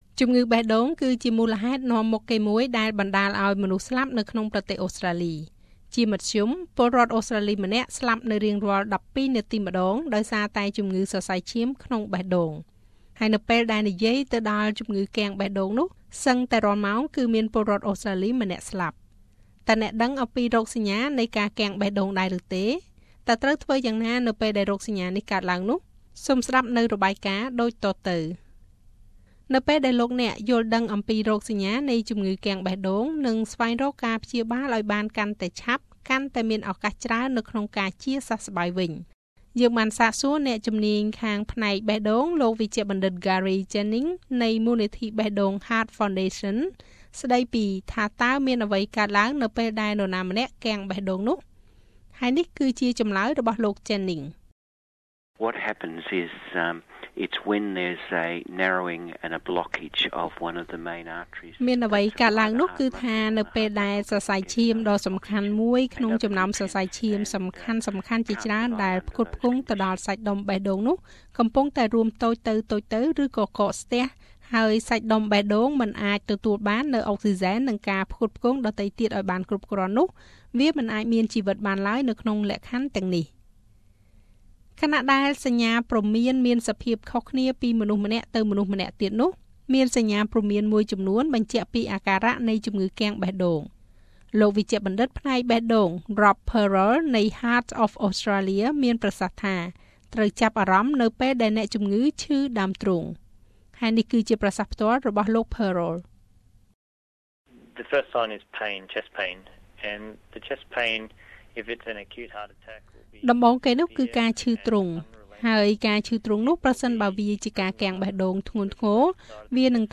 តើអ្នកដឹងពីរោគសញ្ញានៃការគាំងបេះដូងដែរឬទេ? តើត្រូវធ្វើយ៉ាងណានៅពេលដែលរោគសញ្ញានេះកើតឡើង? សូមចុចសំឡេងស្តាប់នូវរបាយការណ៍លំអិត។